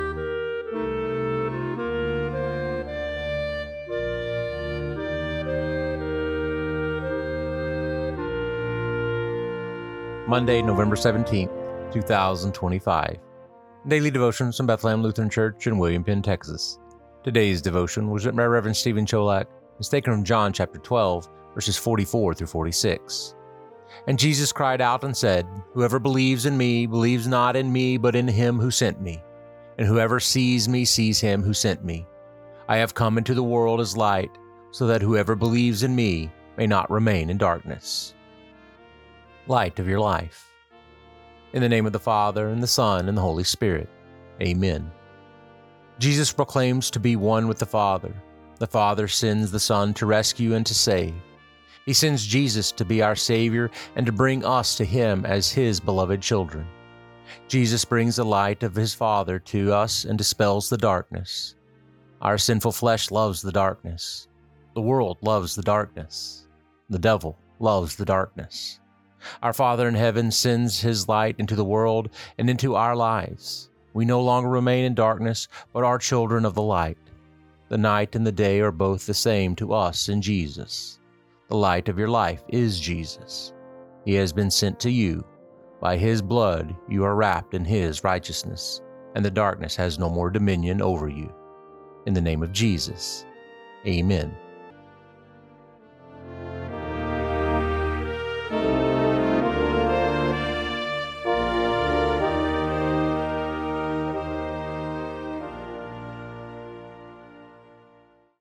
Posted in Daily Devotions